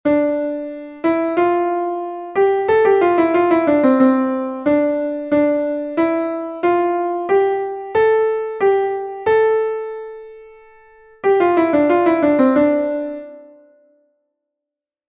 ejemplo_dorico.mp3